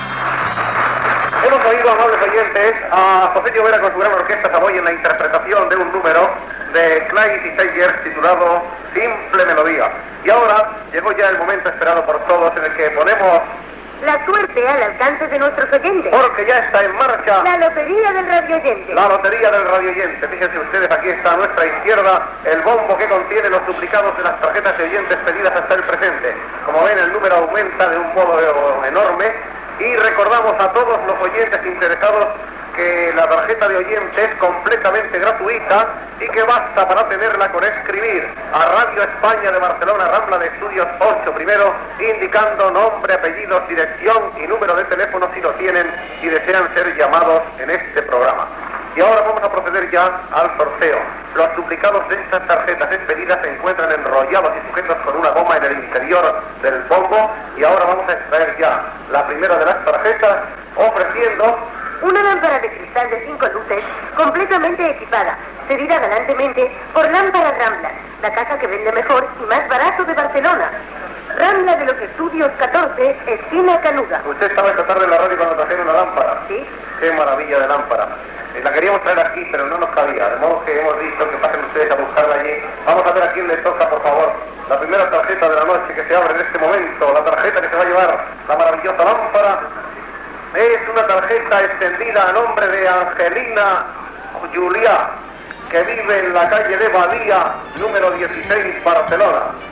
Entreteniment
Extret de "La ràdio a Catalunya" de Ràdio 4 emès l'1 de novembre de 1986